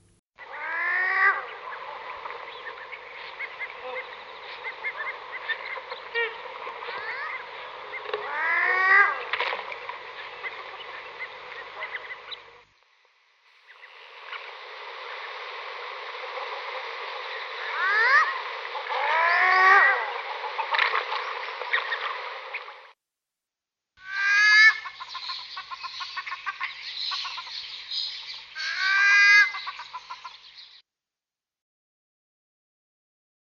Maned Duck
Songs & Calls
The most common call is a loud, rising croaky gnow sound by the females, and the male call is the same except smoother, shorter and higher than the females. Staccato chattering is also present in flocks.
maned-duck-web.mp3